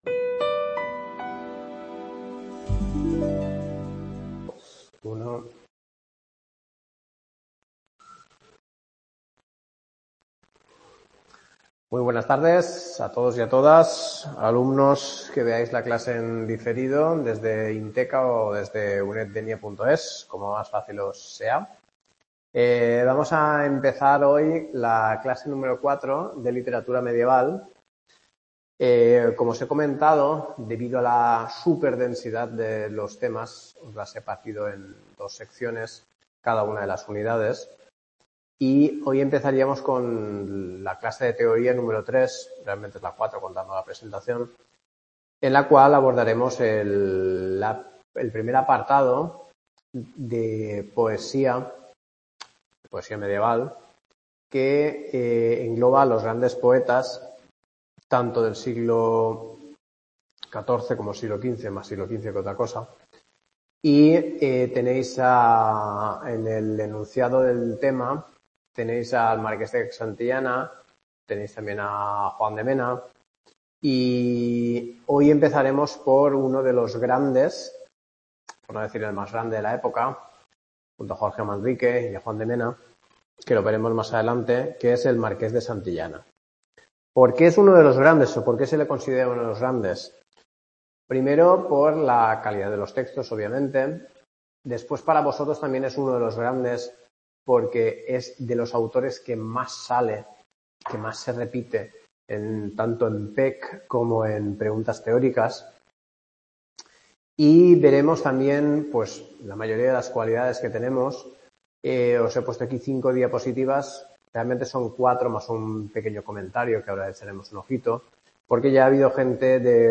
LITERATURA MEDIEVAL CLASE 4 EL MARQUÉS DE SANTILLANA | Repositorio Digital